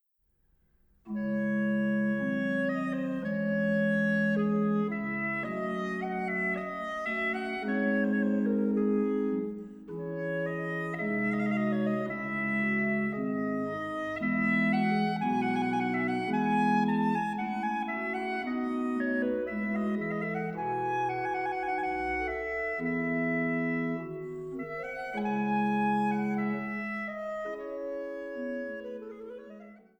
Arie für Sopran, Sopranchalumeau und B. c.